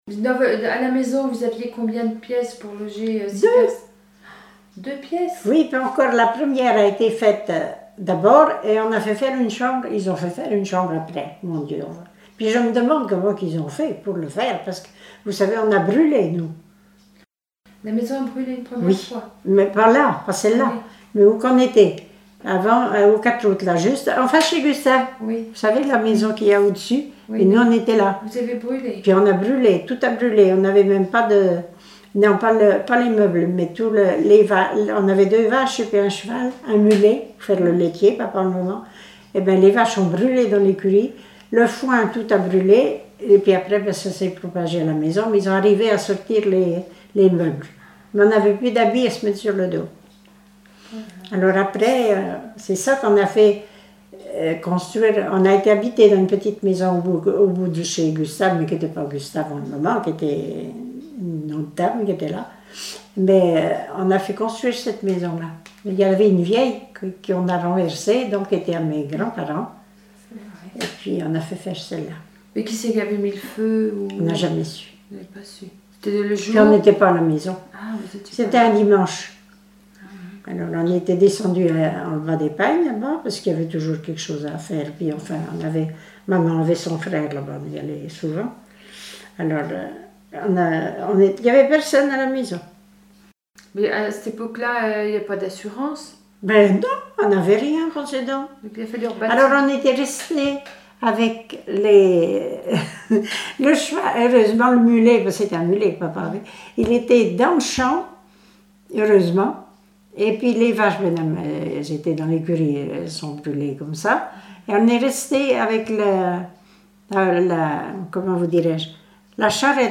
Témoignages sur la vie à la ferme
Catégorie Témoignage